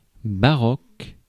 Ääntäminen
Synonyymit bizarre Ääntäminen France: IPA: [ba.ʁɔk] Haettu sana löytyi näillä lähdekielillä: ranska Käännös Substantiivit 1. baroque Adjektiivit 2. baroque Suku: f .